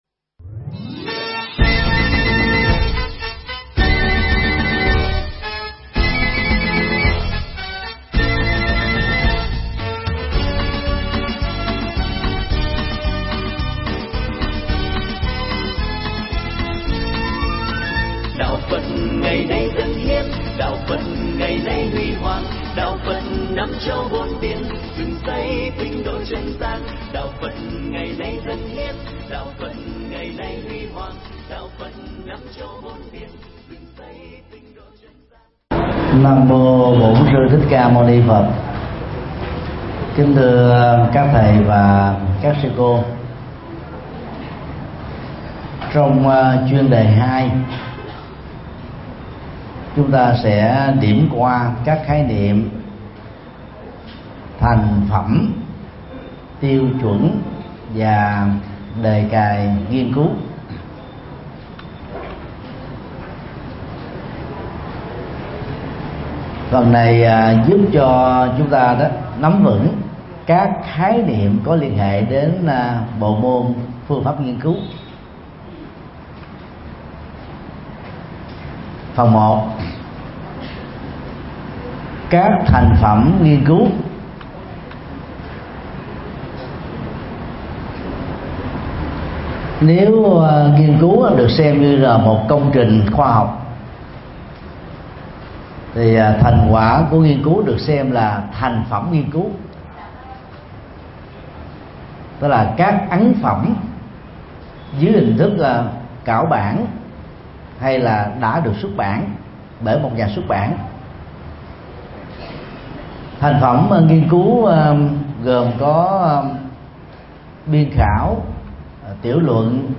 mp3 Pháp Thoại PPNCPH2
giảng tại HVPGVN cơ sở 1 TPHCM